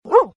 dog-CxIWtkNX.ogg